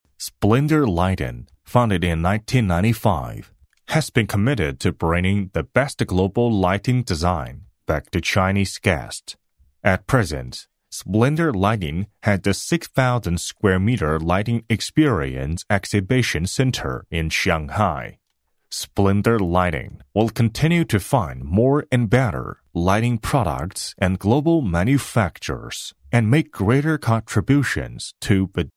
男386-英文简介-《灯饰》
男386-中英双语 大气浑厚
男386-英文简介-《灯饰》.mp3